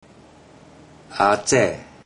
Click each Romanised Teochew word to listen to how the Teochew word is pronounced.
a3ze4